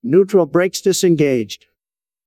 neutral-brakes-disengaged.wav